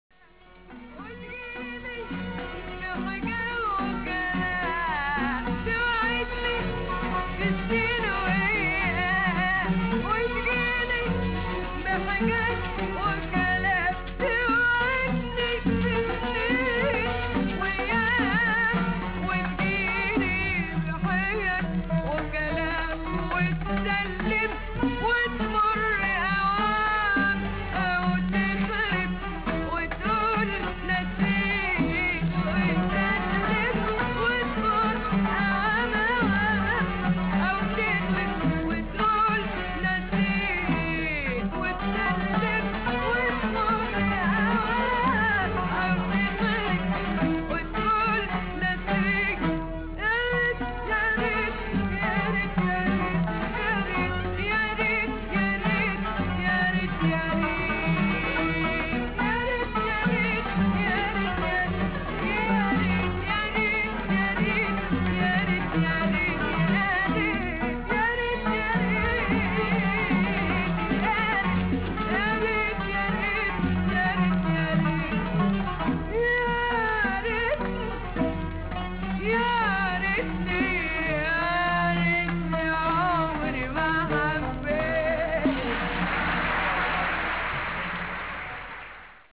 Dialect: Egyptian Colloquial Maqam: Higaz